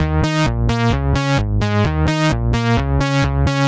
Moogin Fours Eb 130.wav